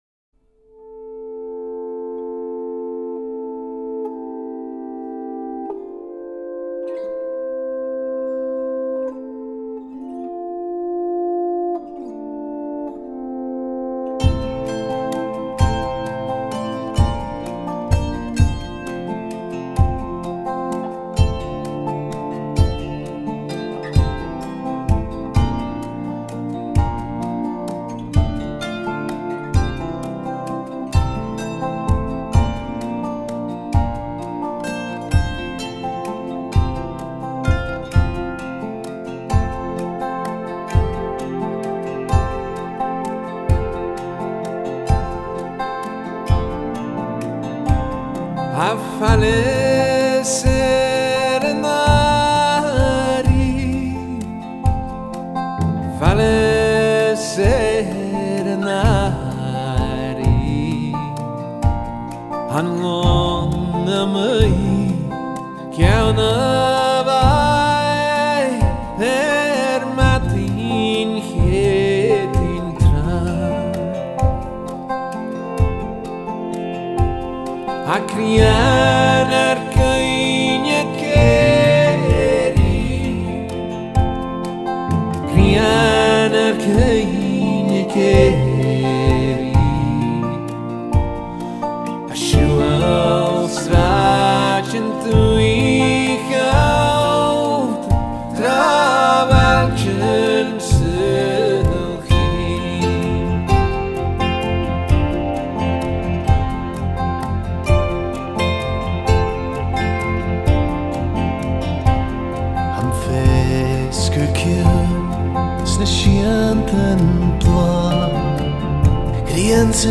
Style: Folk-Rock